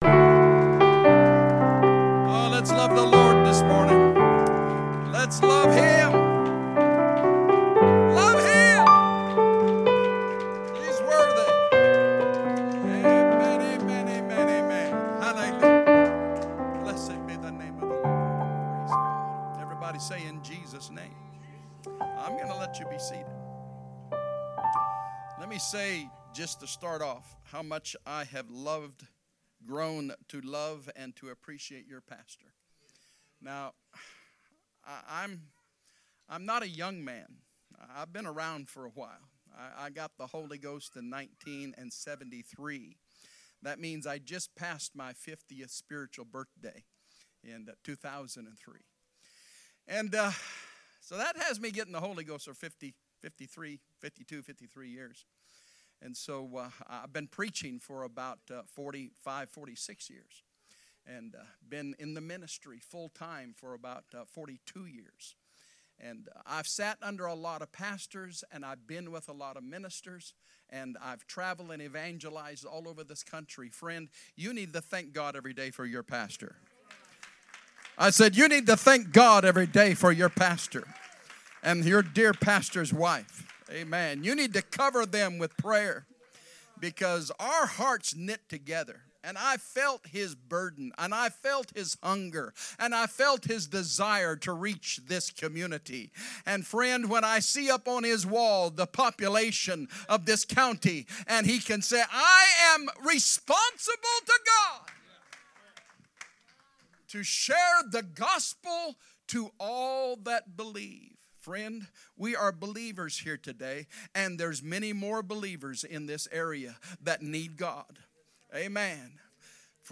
Sermons by Breaking Bread Apostolic Church